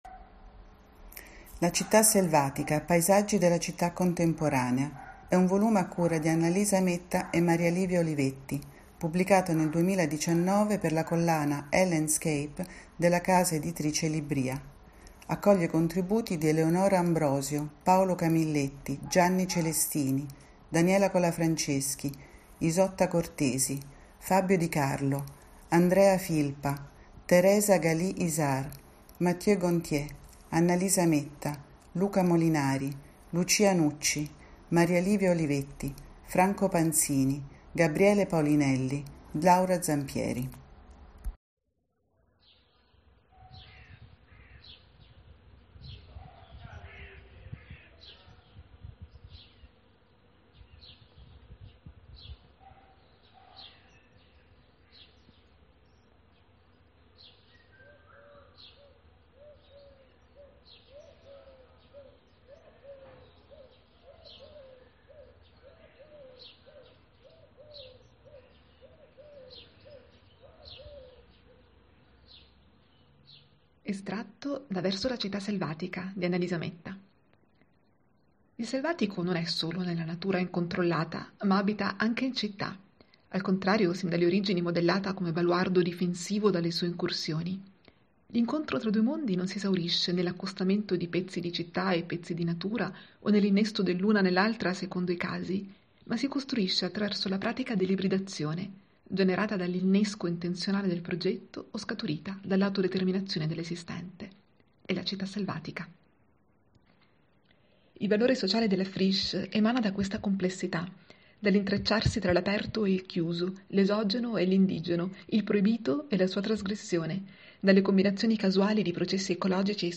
Reading.
In questo podcast, alcuni degli autori dei saggi raccolti nel volume leggono brevi estratti dei propri testi.